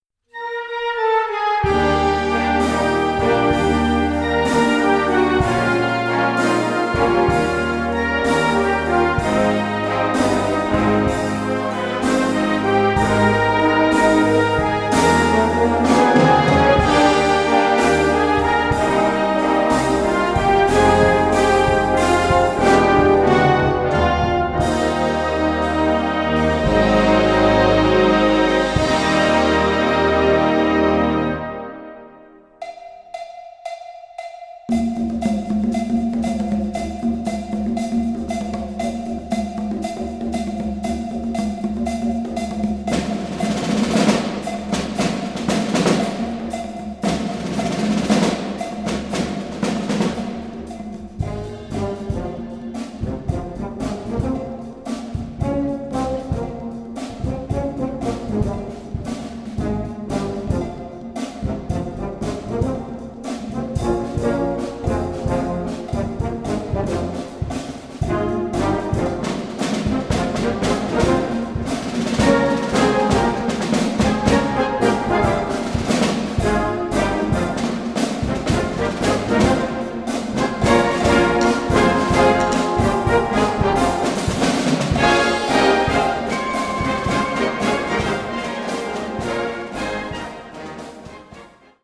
Brass Band
Music With Basler Drums / Musique avec tambours